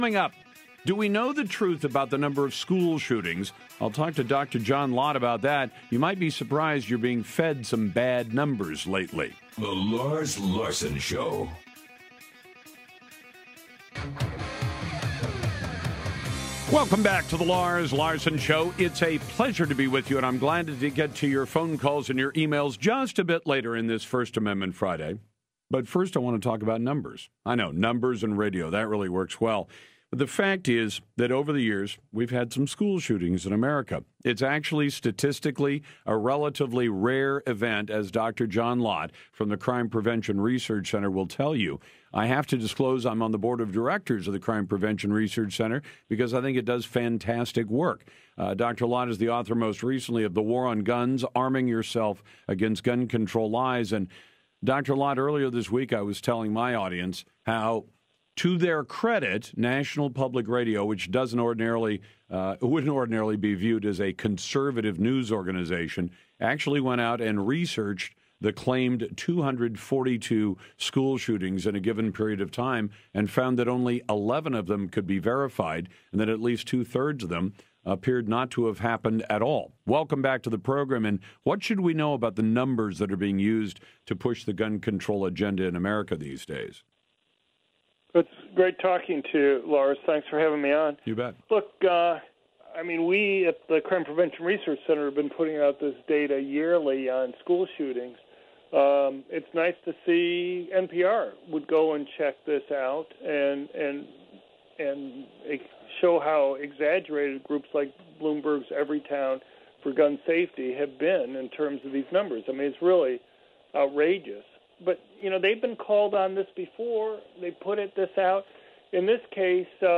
media appearance
Dr. John Lott was on the national Lars Larson show to discuss a recent report by NPR about the grossly exaggerated numbers involving school shootings and our own new research on mass public shooting rates in countries around the world.